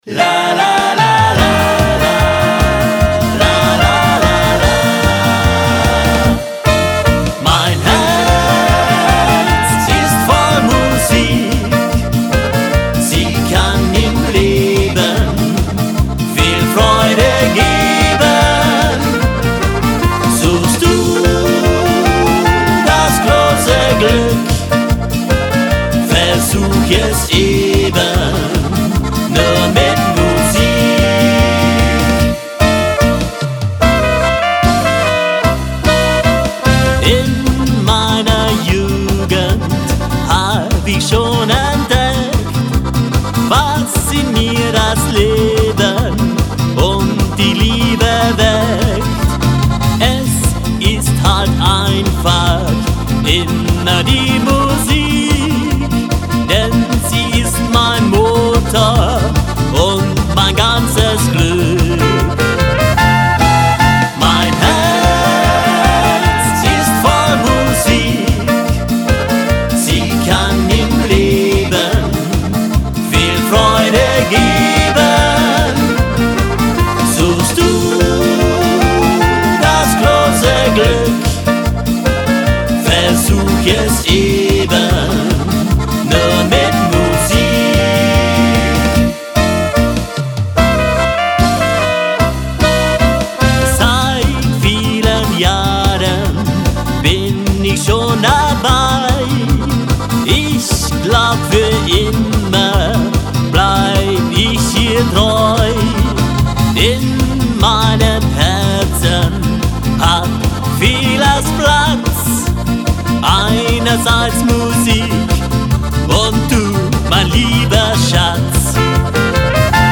Polkalied